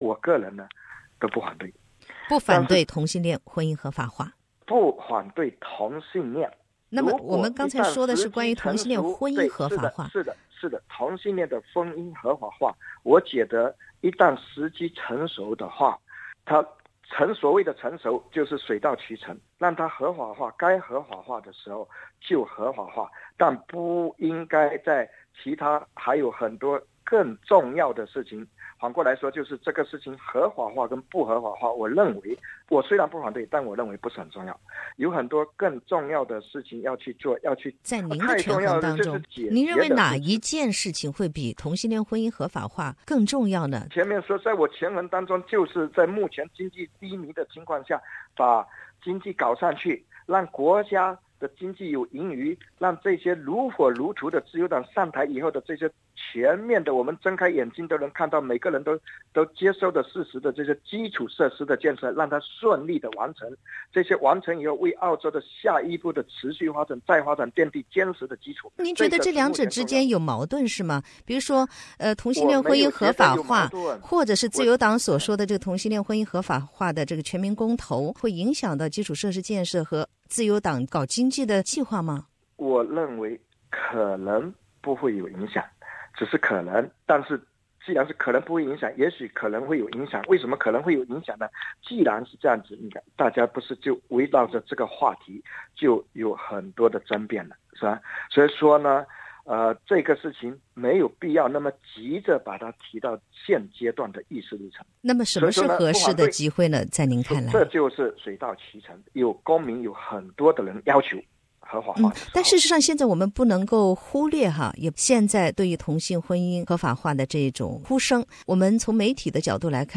请听完整采访录音 （以上仅为个人观点） READ MORE 华人谈大选：2016联邦大选等你发声！